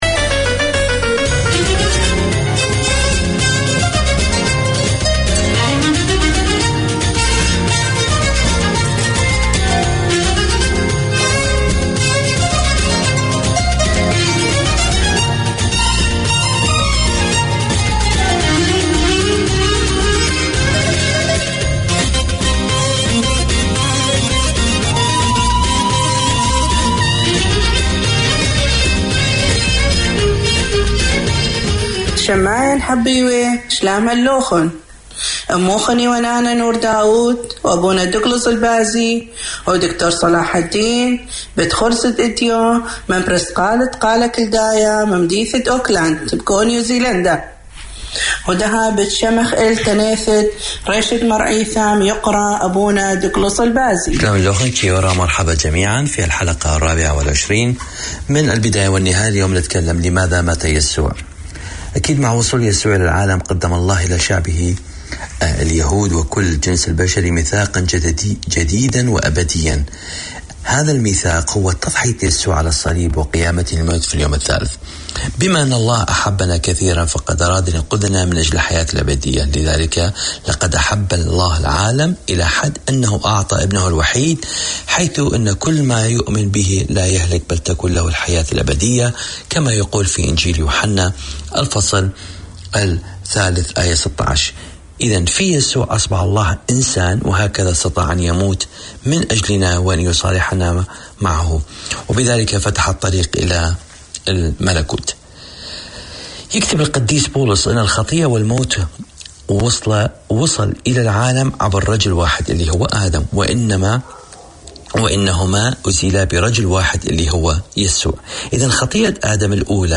Catering to a wide community of Arabic, Syriac, Chaldean and Kurdish speakers, Voice of Mesopotamia presents an engaging and entertaining hour of radio. Tune in for interviews with both local guests and speakers abroad, a youth-led segment and music from across Mesopotamia.